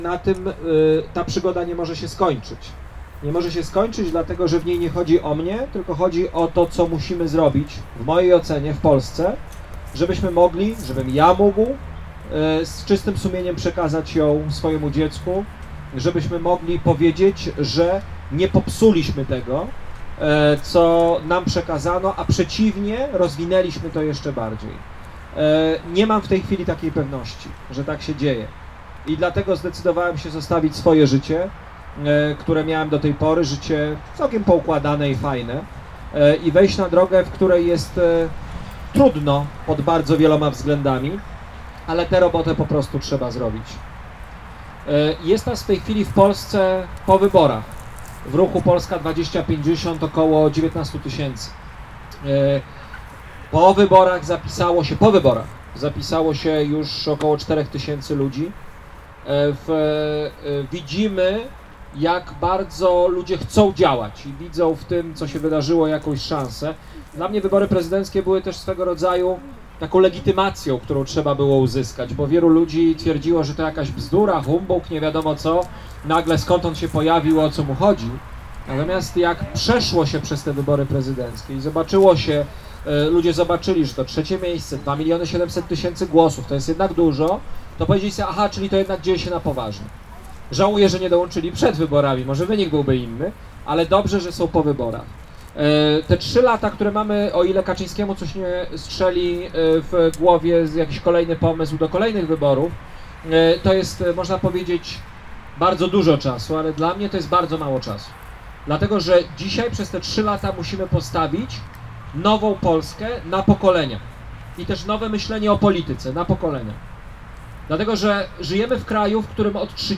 SzHolownia_Suwalki.mp3